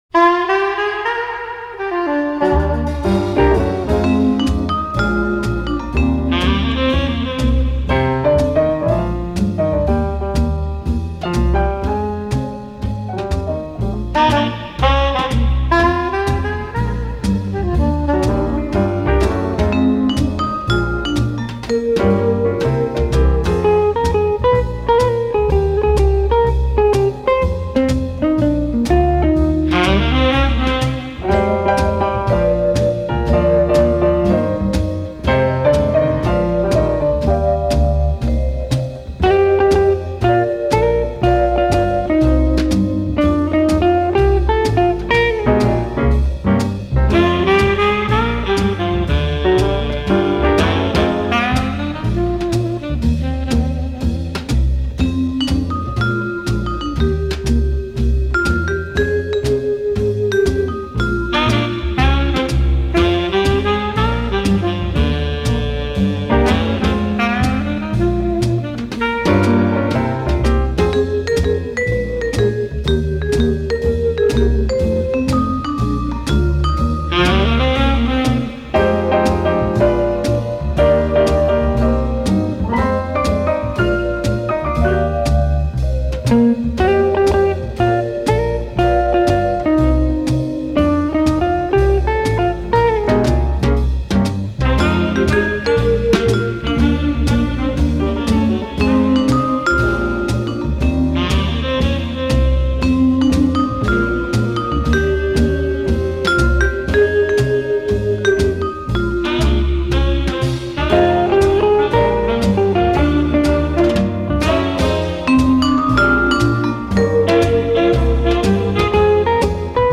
Жанр: Bossa Nova.